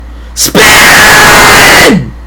PLAY spinning wheel sound
spin.mp3